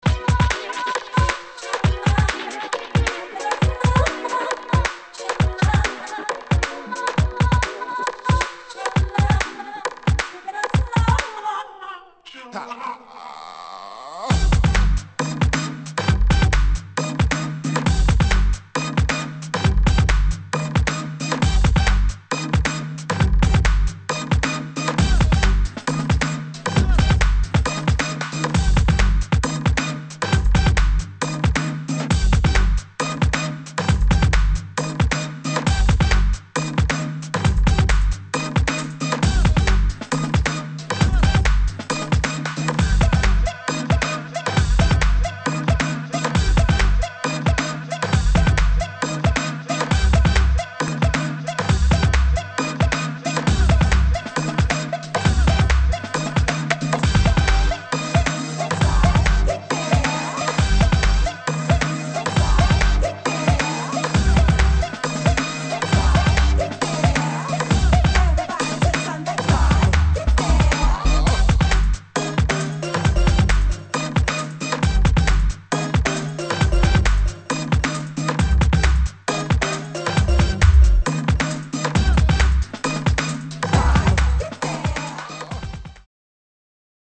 [ DUBSTEP / UK GARAGE ]